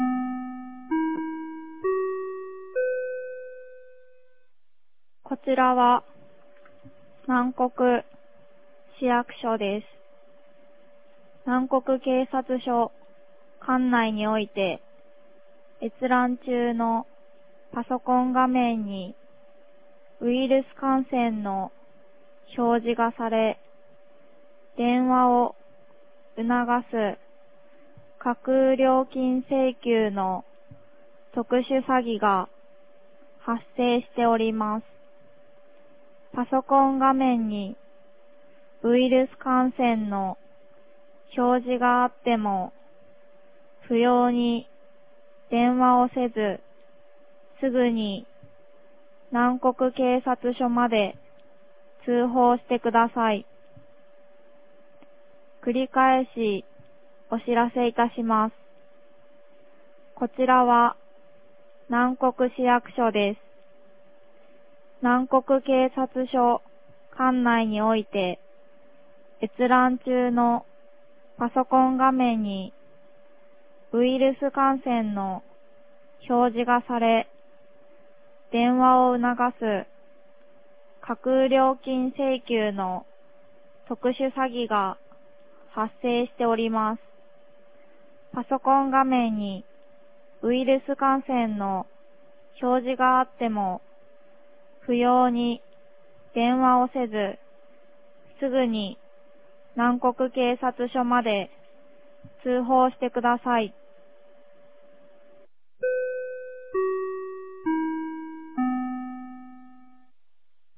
2023年05月08日 14時51分に、南国市より放送がありました。
放送音声